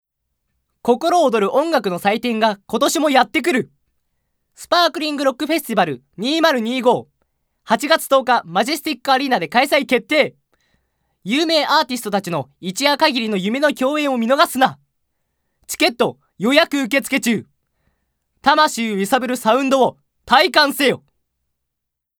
高音出ます。
ボイスサンプル、その他
ナレーション１